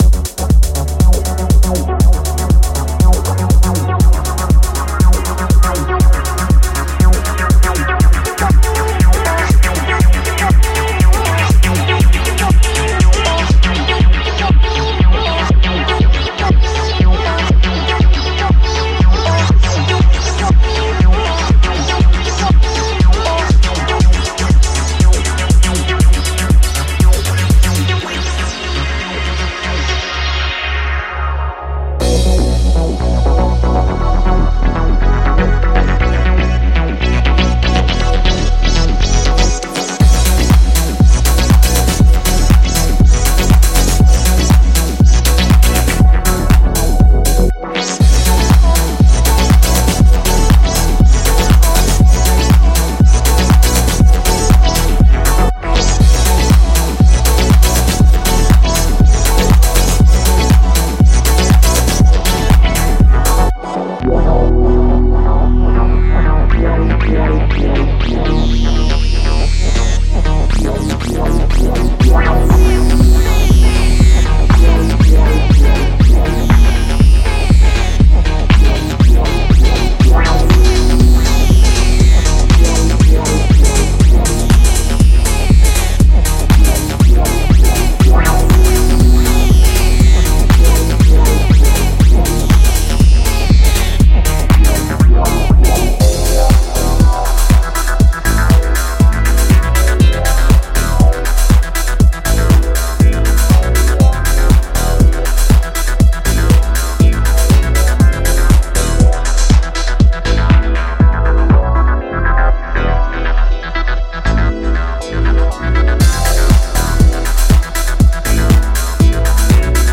Explore a diverse selection of 82 essential loops that capture the soulful essence and infectious grooves of classic house music. From iconic drum rhythms and funky basslines to lush chord progressions and euphoric synth leads, each loop is designed to inject authenticity and energy into your tracks.
Vintage House Vibes: Immerse yourself in the unmistakable sounds of classic house music, inspired by the legends of the genre.
High-Quality Audio: Each loop is professionally recorded and mastered to ensure optimal sound quality, giving your productions that polished, studio-ready finish.
10 bass loops
25 drum loops
29 synth loops
8 vocal loops